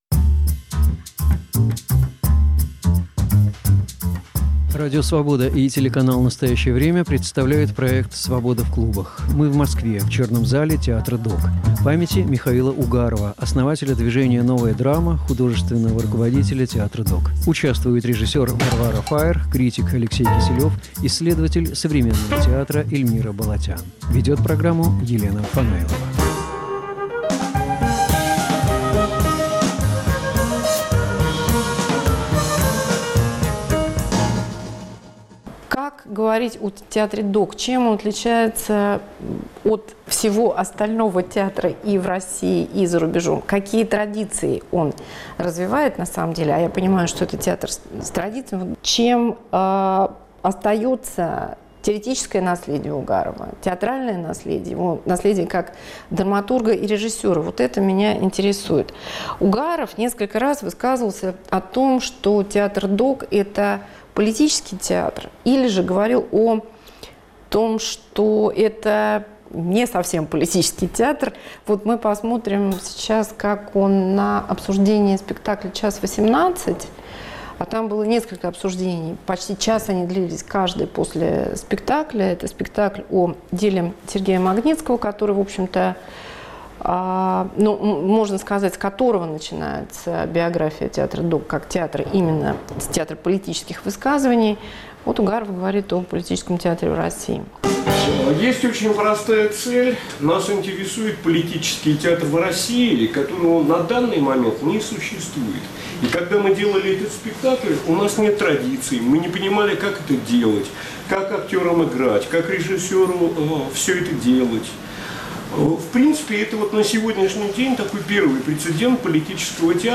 Памяти основателя движения "Новая драма" и Театра.док. Говорят критики и ученики.